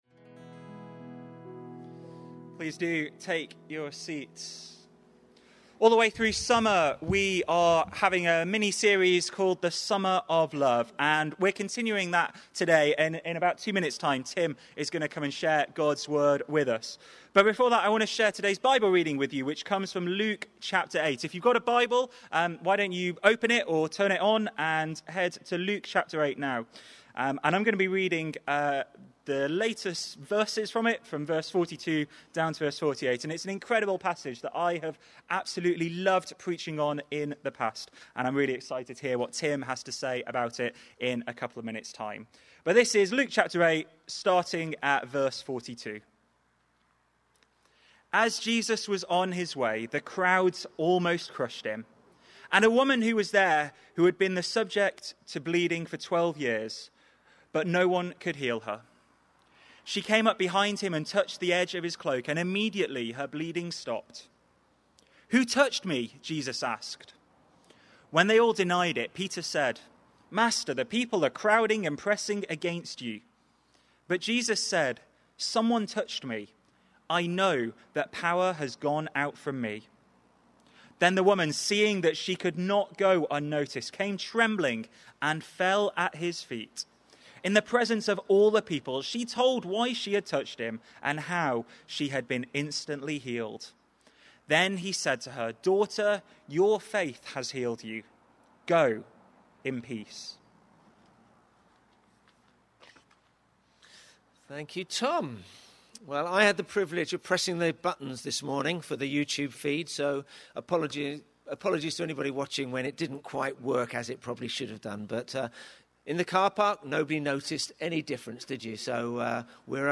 Today’s sermon is …